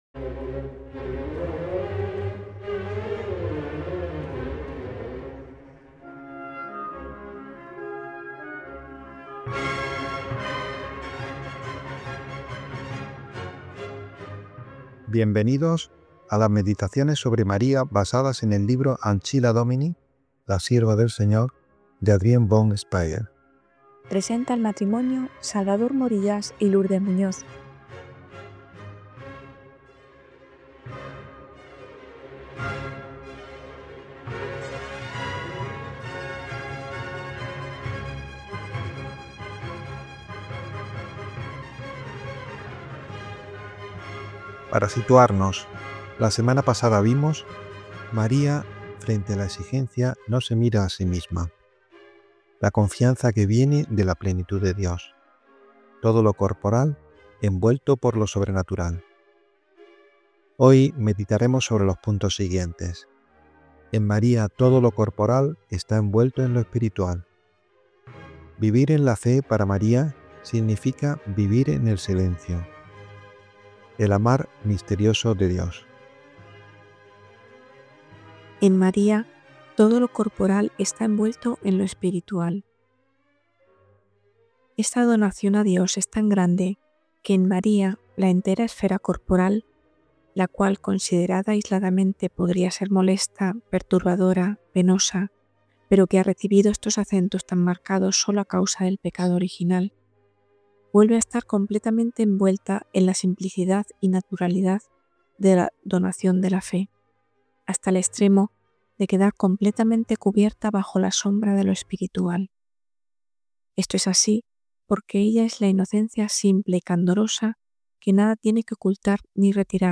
El sí de la Virgen María y su entrega sin límites nos presenta el modelo de la actitud cristiana. Este podcast profundiza en esta realidad a través de la lectura y la meditación del libro Ancilla Domini, de la mística suiza Adrienne von Speyr.